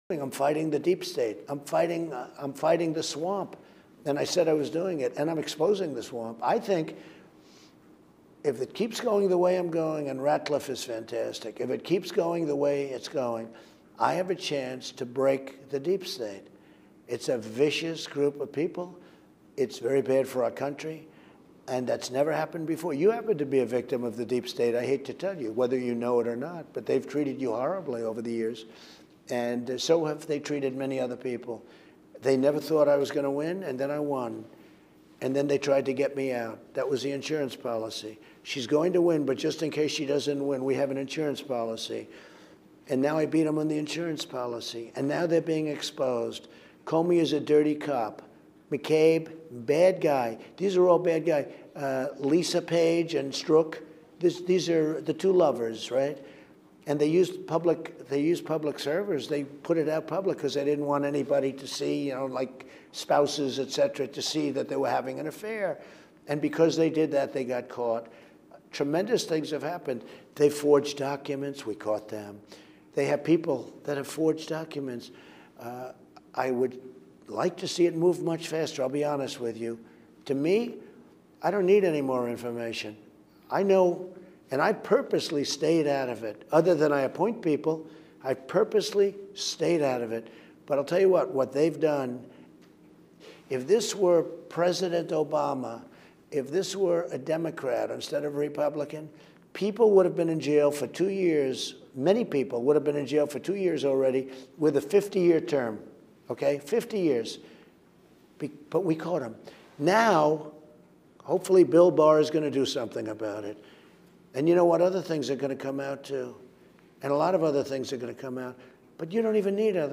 Trump-Interview.mp4